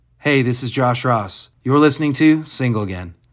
LINER Josh Ross (Single Again) 3